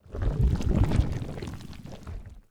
stomachmove.ogg